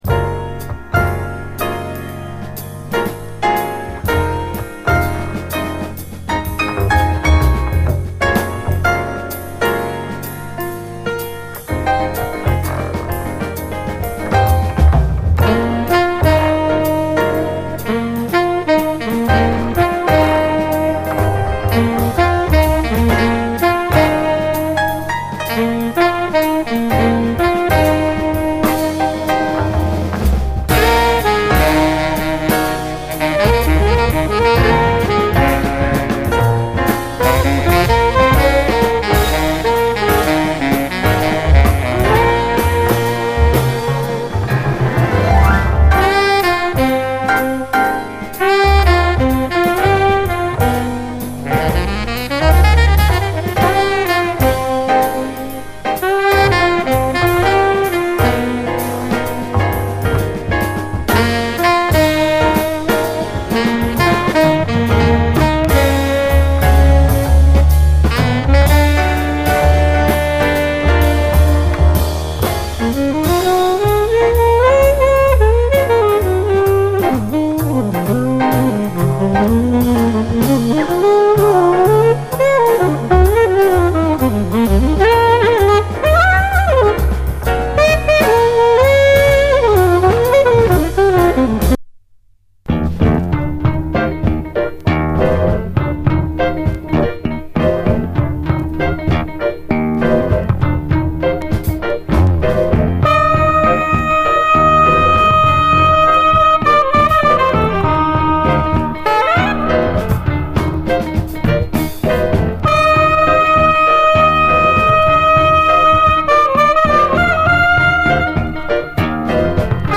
JAZZ FUNK / SOUL JAZZ, JAZZ